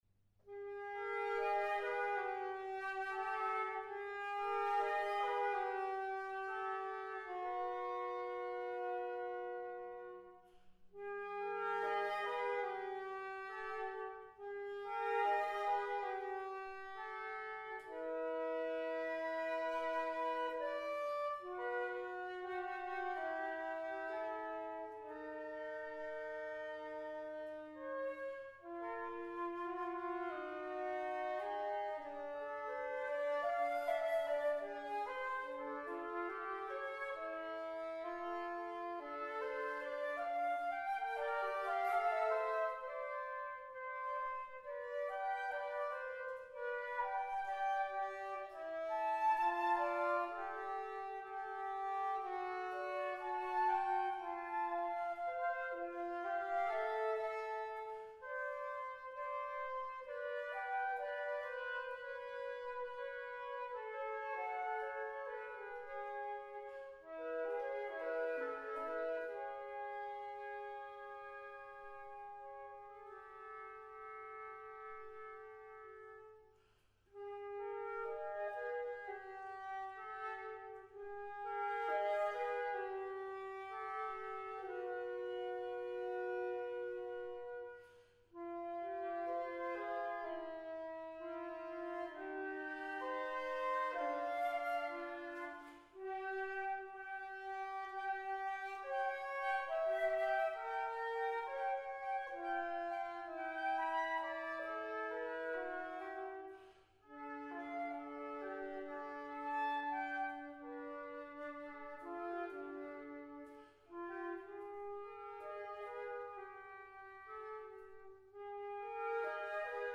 Shop / Noten / Flötenduette
• für 2 Flöten